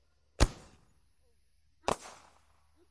烟花 " 烟花30
描述：使用Tascam DR05板载麦克风和Tascam DR60的组合使用立体声领夹式麦克风和Sennheiser MD421录制烟花。我用Izotope RX 5删除了一些声音，然后用EQ添加了一些低音和高清晰度。
Tag: 高手 焰火 裂纹